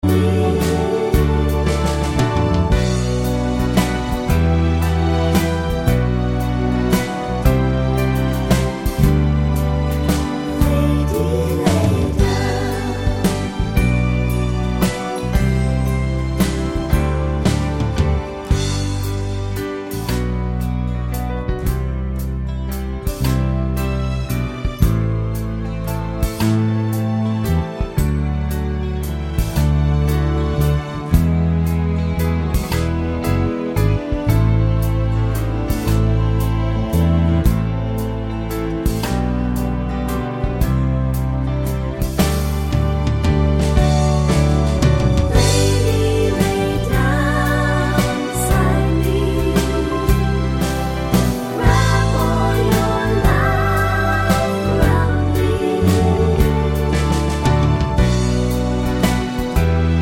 no Backing Vocals Crooners 3:22 Buy £1.50